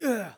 xys被击倒2.wav 0:00.00 0:00.38 xys被击倒2.wav WAV · 33 KB · 單聲道 (1ch) 下载文件 本站所有音效均采用 CC0 授权 ，可免费用于商业与个人项目，无需署名。
人声采集素材